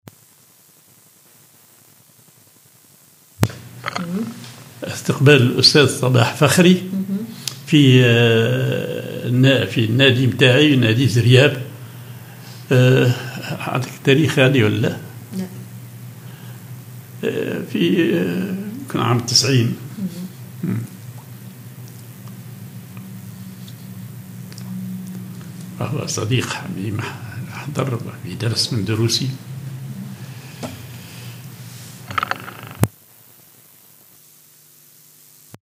en Reception for Professor Sabah Fakhri at the Ziryab Club
en Professor Salah El Mahdi with Professor Sabah Fakhri